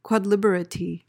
PRONUNCIATION:
(kwod-LIB-uh-ter-ee)